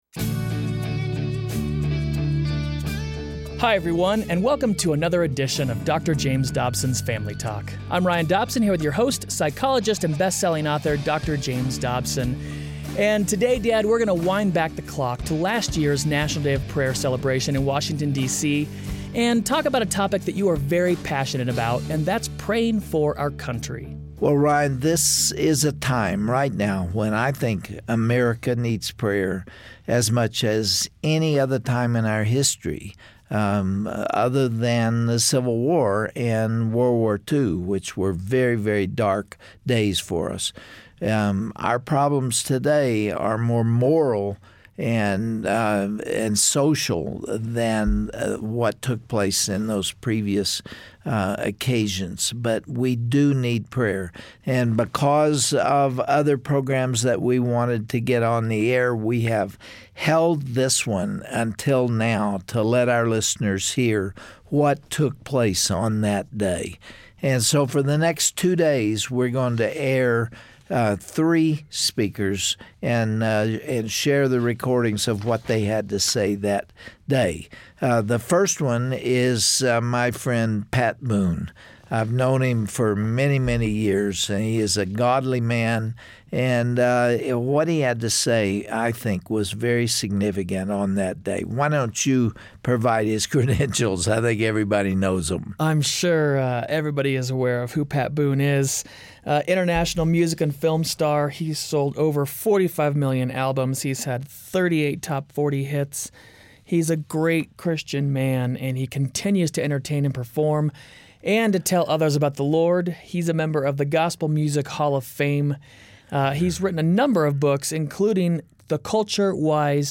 Pat Boone reflects on the history of prayer in America and Chaplain Barry Black speaks on why we should pray for not only our country, but our leaders as well, at the National Day of Prayer 2013.